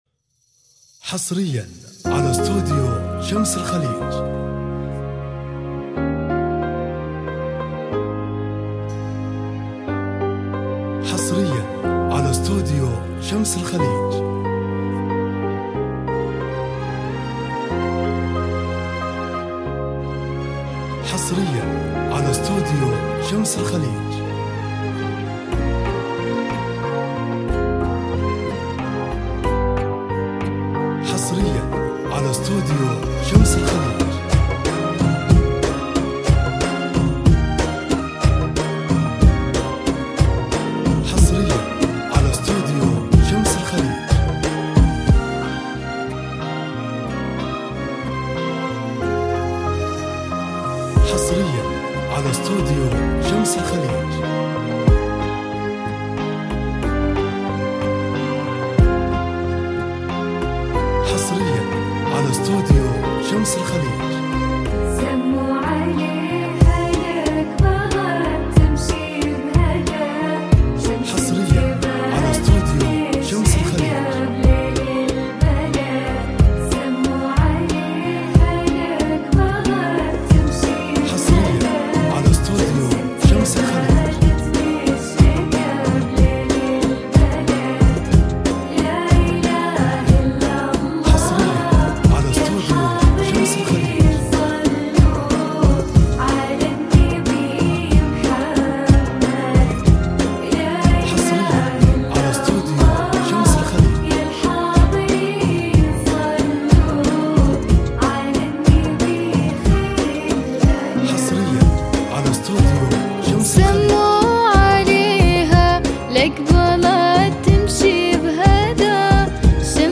زفات موسيقى